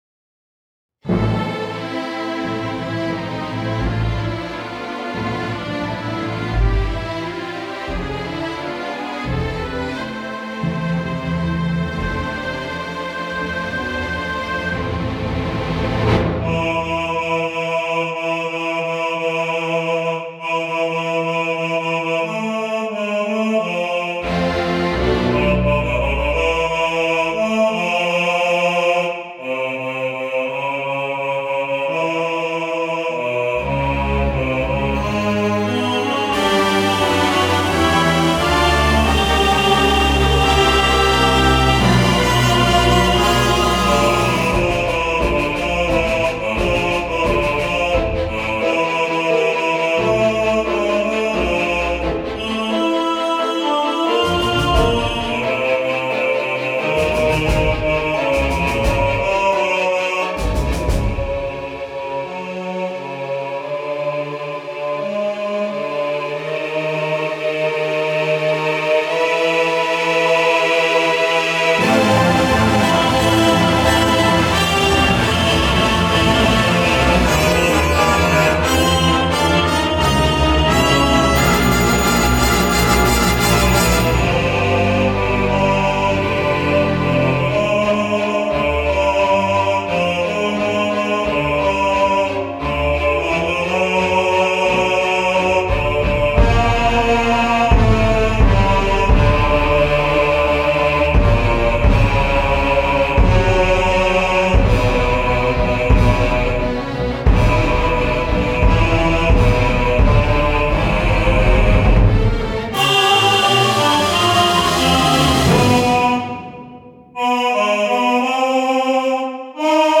Genre: Opera